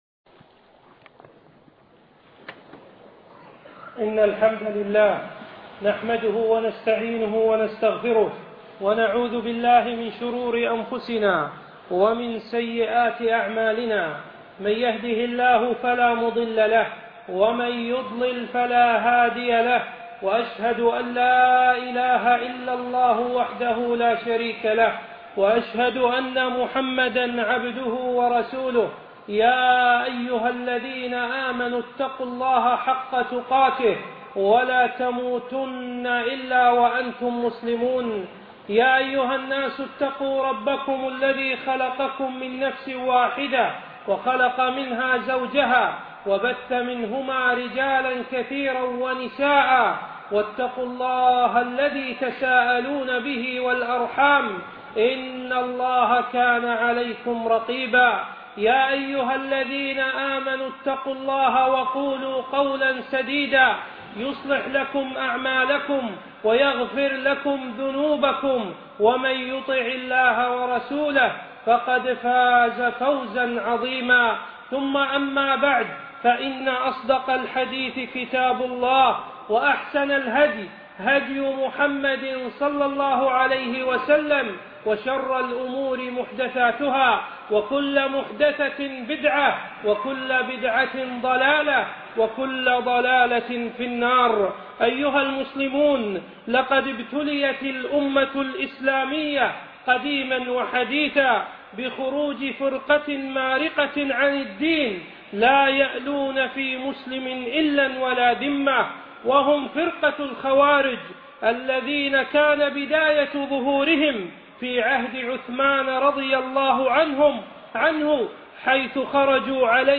خطبة بتاريخ 6 2 2015